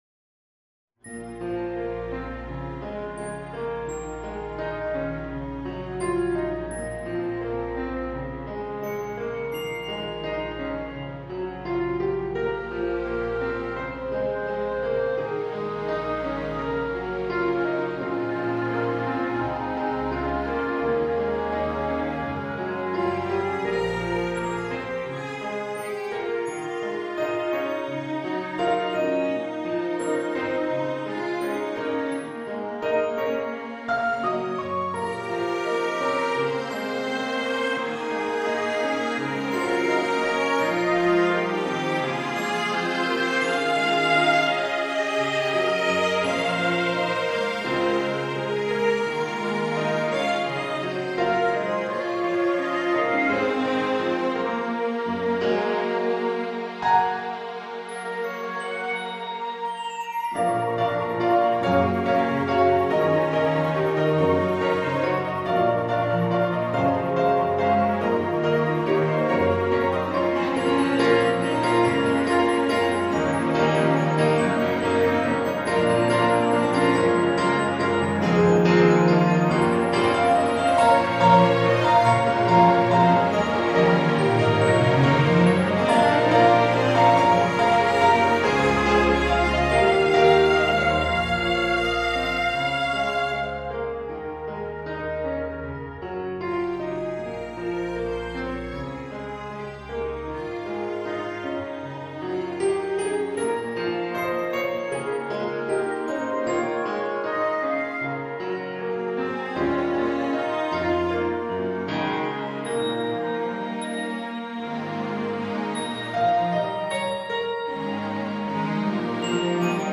Serio